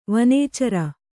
♪ vanēcara